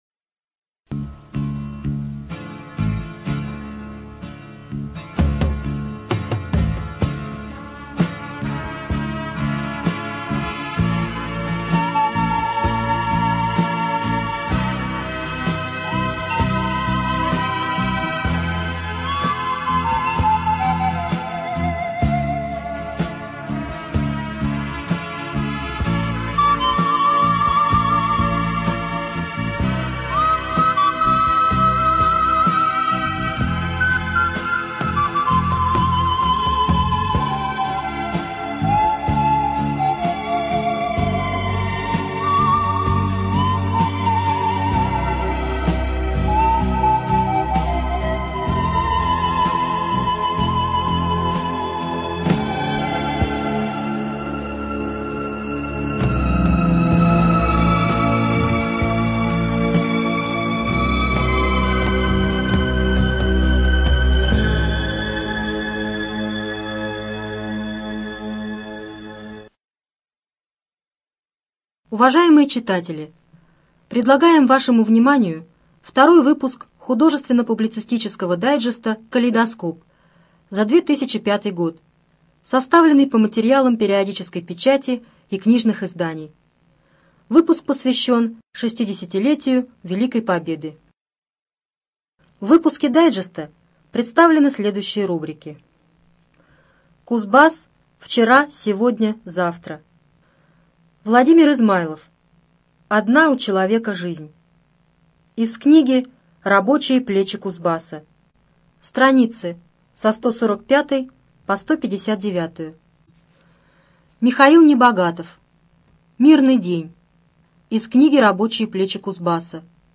Студия звукозаписиКемеровская областная специальная библиотека для незрячих и слабовидящих